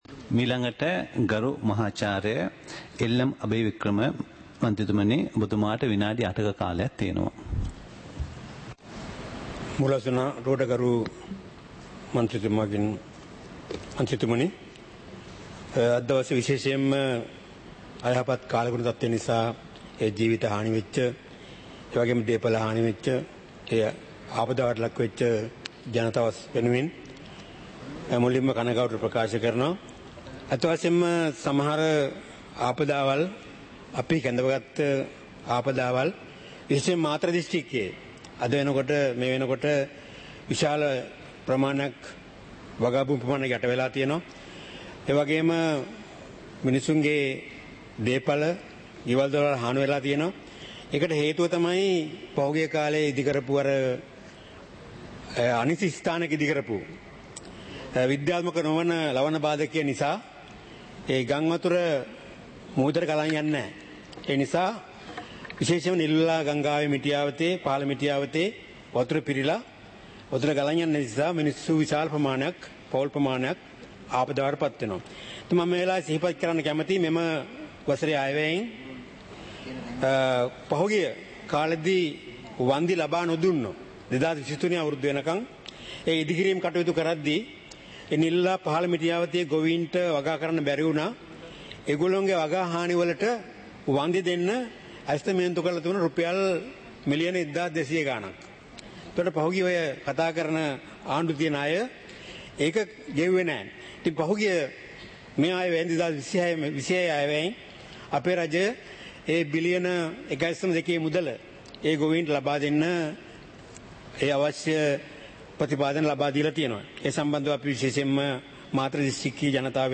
සභාවේ වැඩ කටයුතු (2025-11-27)
පාර්ලිමේන්තුව සජීවීව - පටිගත කළ